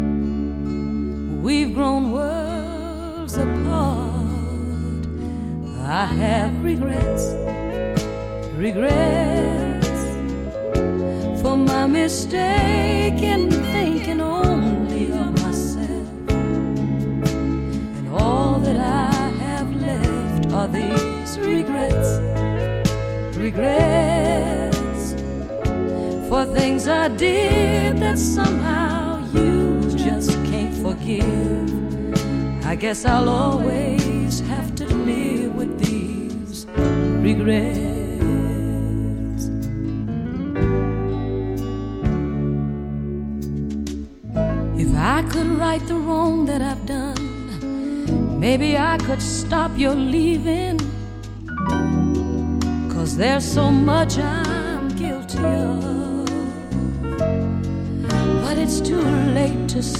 Smooth breezy soulful AOR
remastered and released on vinyl for the first time.